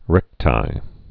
(rĕktī)